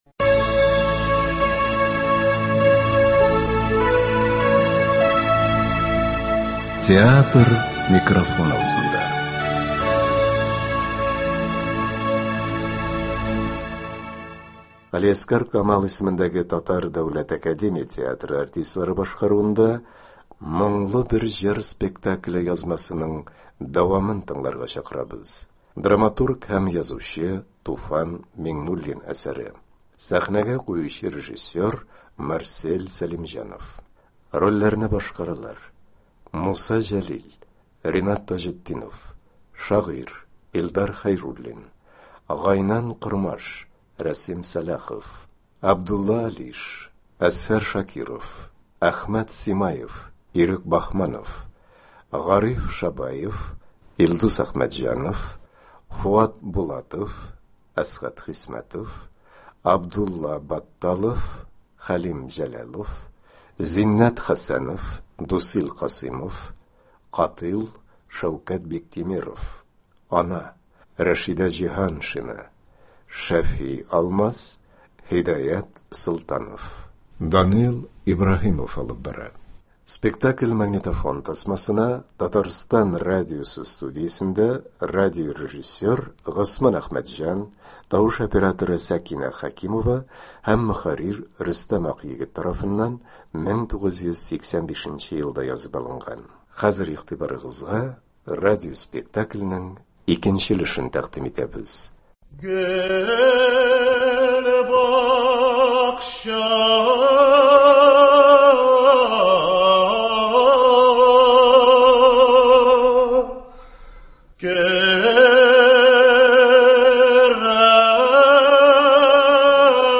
Г.Камал исемендәге Татар Дәүләт академия театры артистлары башкаруында
ТДАТ спектакленең радиоварианты.